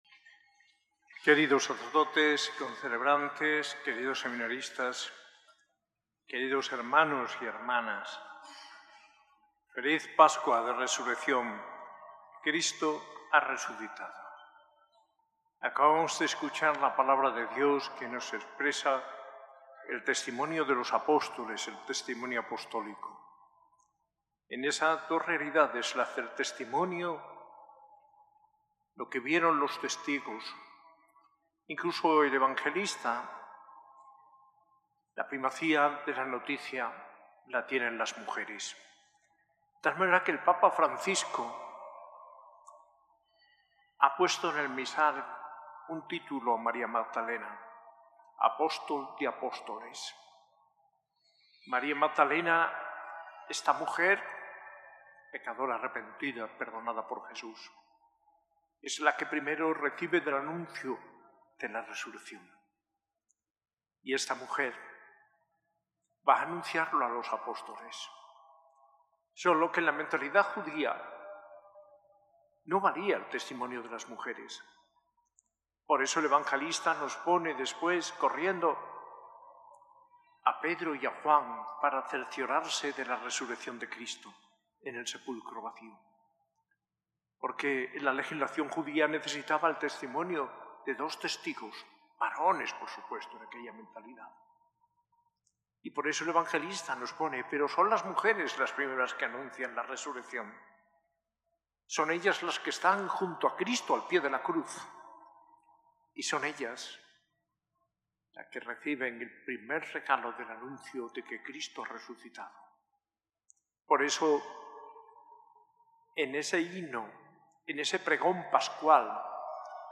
Homilía del arzobispo de Granada, Mons. José María Gil Tamayo, en la celebración del Domingo de Resurrección, el 20 de abril de 2025, en la S.A.I Catedral.